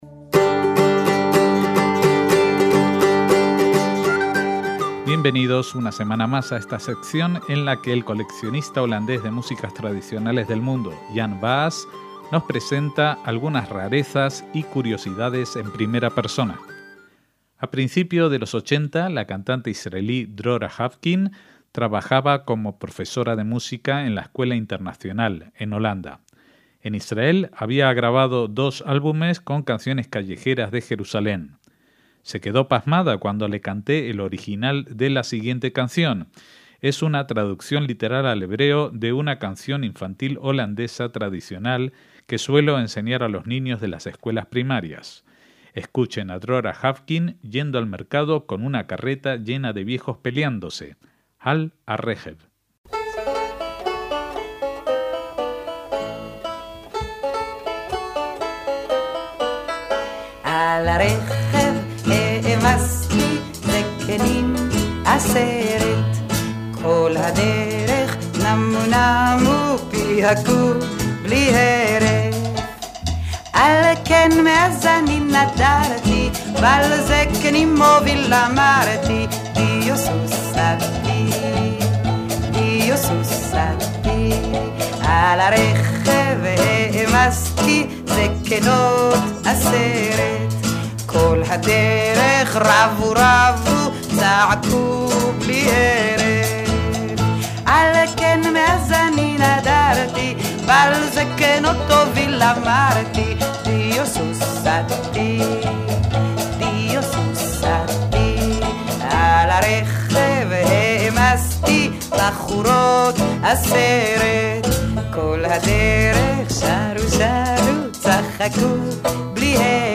Canciones infantiles holandesas de Israel